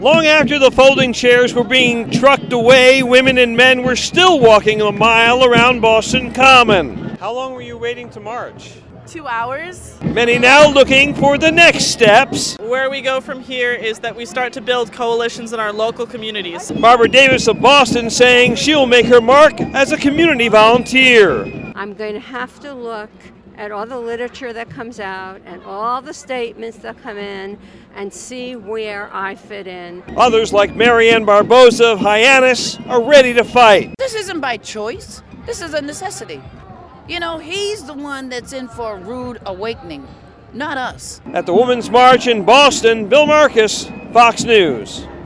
FILED THIS REPORT: